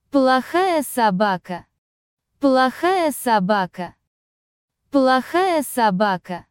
Capitals for stress, "O" like in "poll", "A" like "u" in "sun", "E" like in "bell"
BAD DOG - PLOHAYA SOBAKA (plo-KHA-ya), ПЛОХАЯ СОБАКА